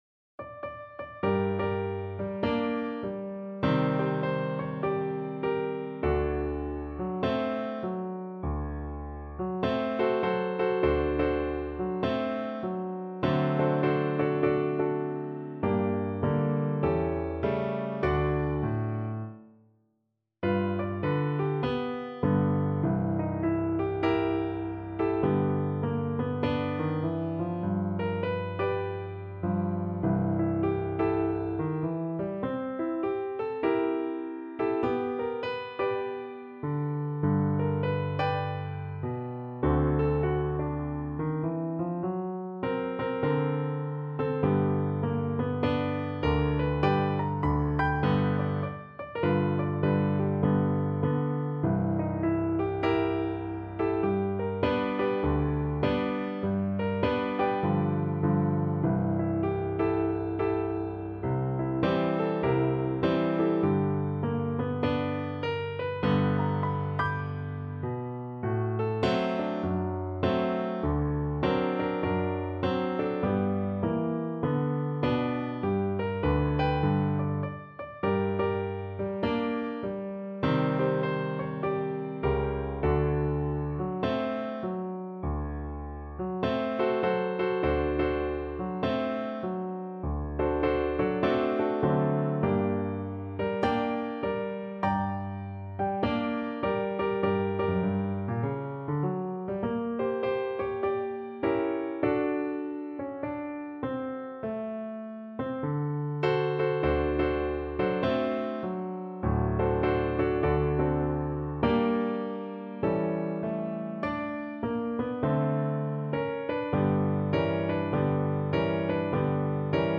4/4 (View more 4/4 Music)
Jazz (View more Jazz Double Bass Music)